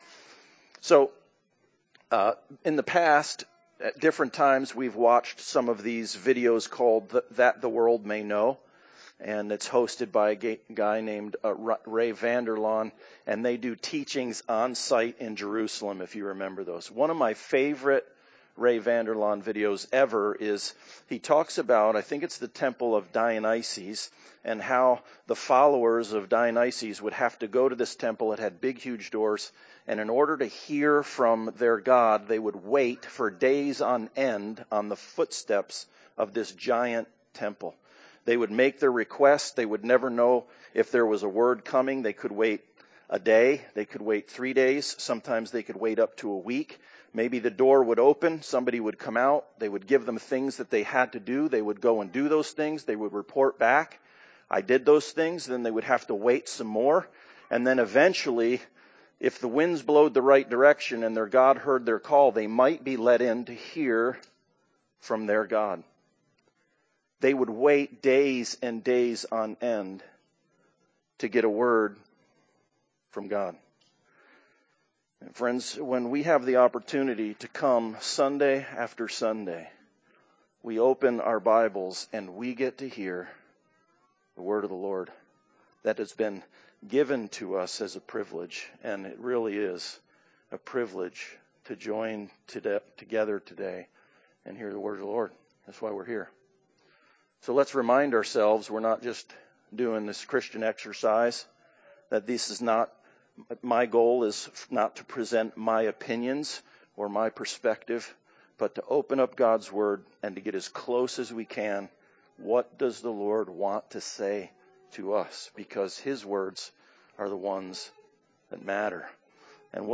Passage: Galatians 6:1-5 Service Type: Sunday Service Bible Text